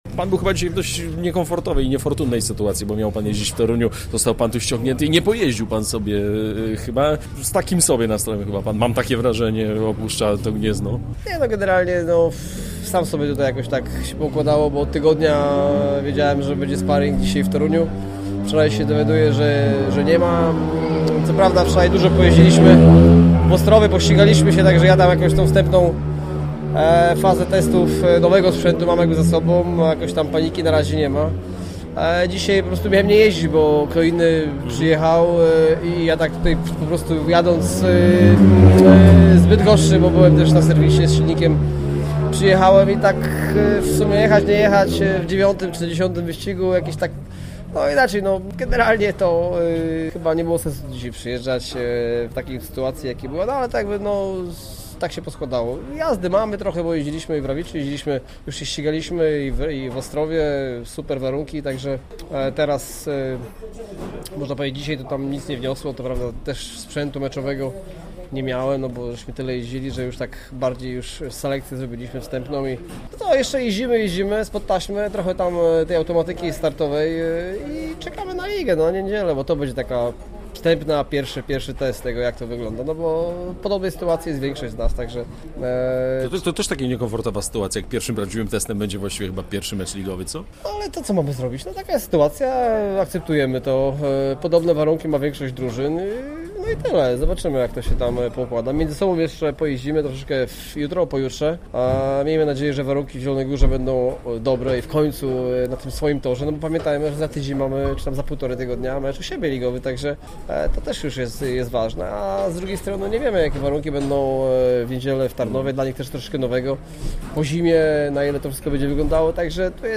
Po meczu poprosiliśmy go o rozmowę o aktualnej dyspozycji i zbliżającym się meczu z Unią Tarnów.